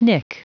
Prononciation du mot nick en anglais (fichier audio)
Prononciation du mot : nick